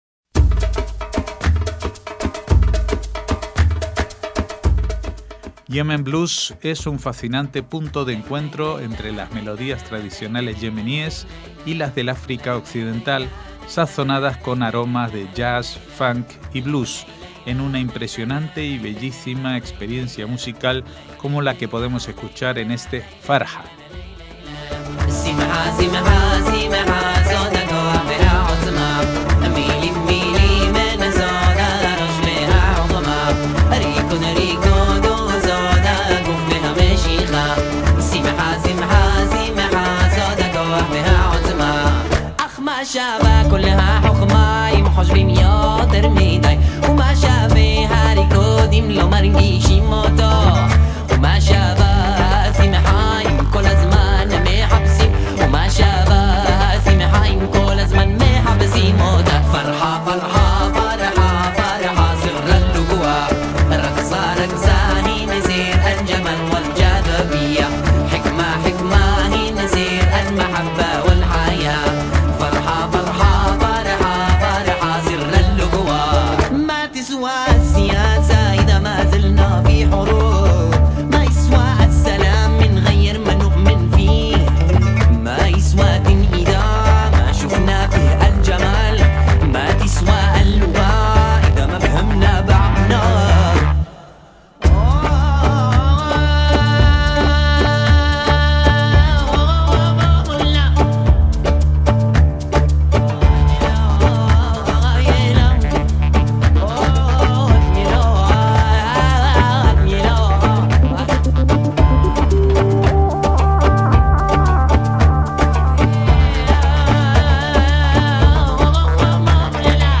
trombón
trompeta
percusiones latinas
percusiones orientales
chelo
viola
flauta